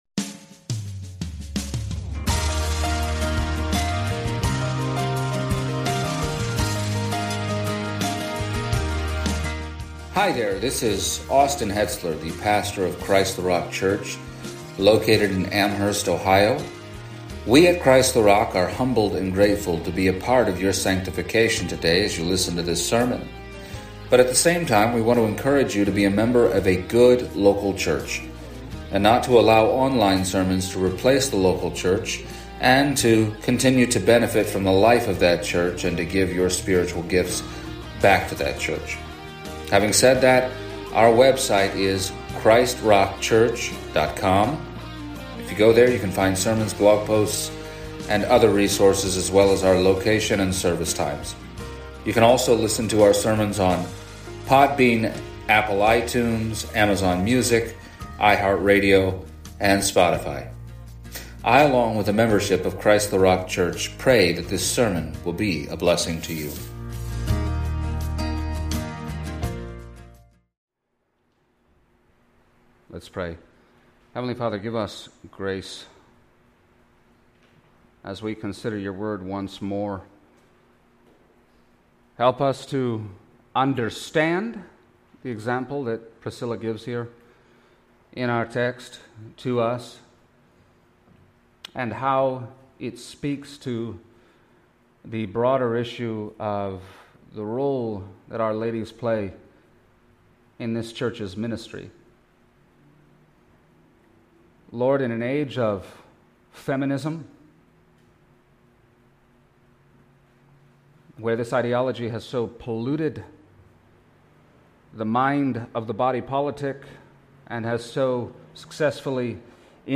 Passage: Acts 18:24-28 Service Type: Sunday Morning